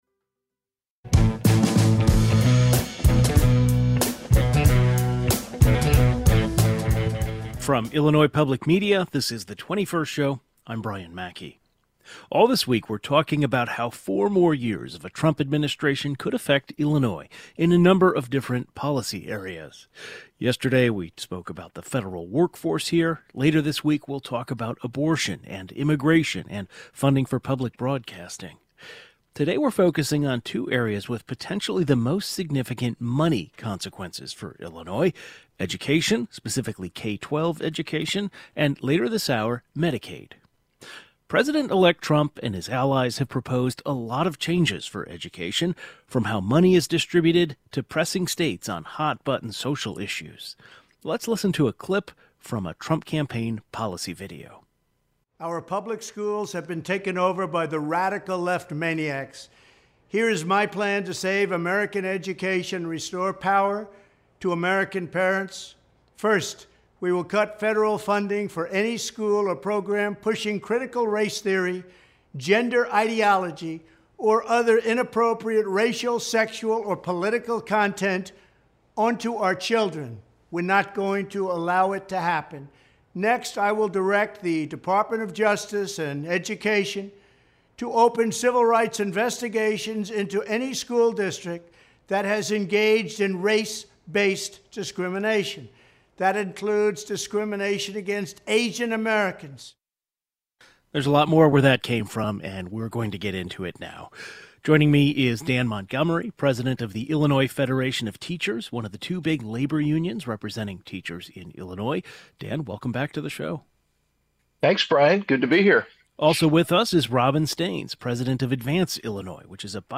A panel of education policy experts discuss what Trump's proposals and ideas for education reform mean and how they could impact Illinois schools.